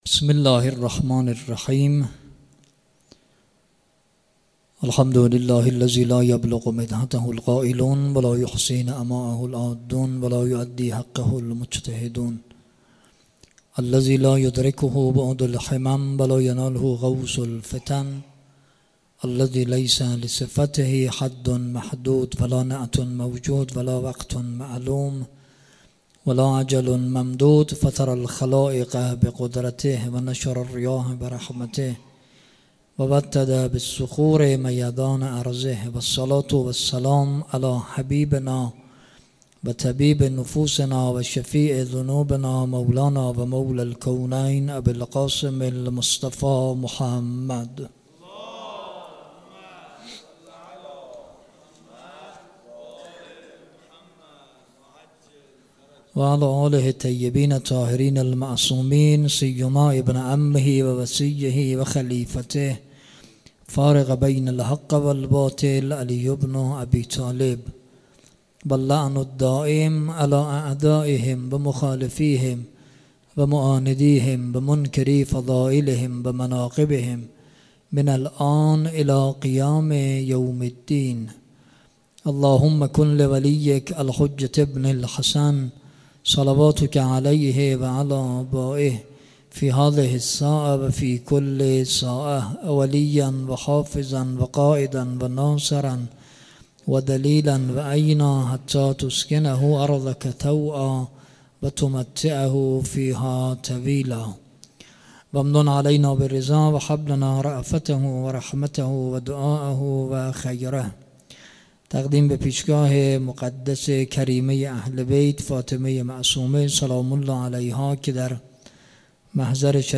شب دوم رمضان 96 - دار التلاوه مسجداعظم - سخنرانی